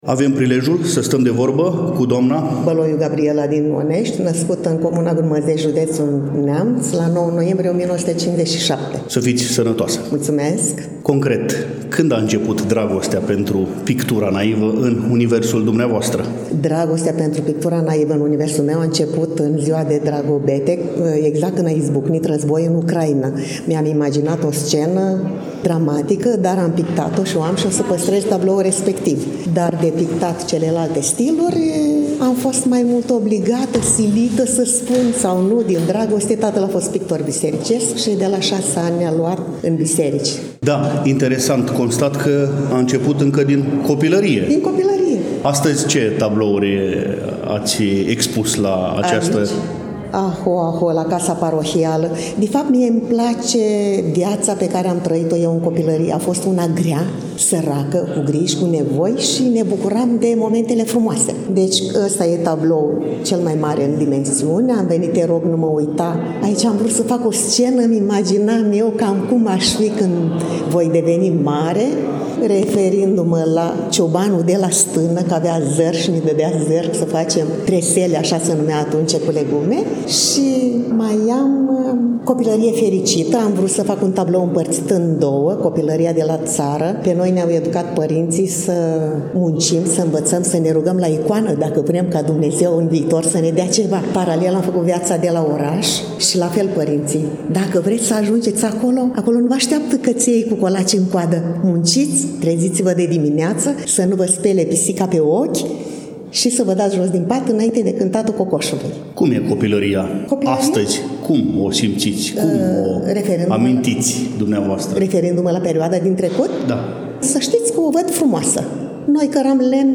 Manifestarea s-a desfășurat în ziua de vineri, 25 aprilie 2025, începând cu ora 11.